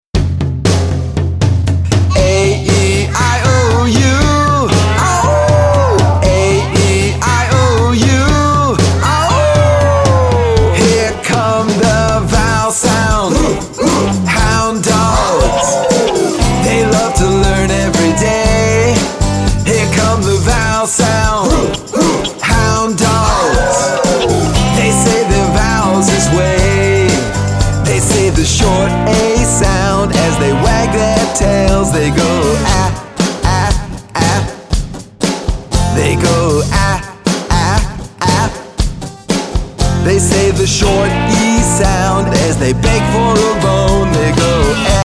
Listen to the open version of this song.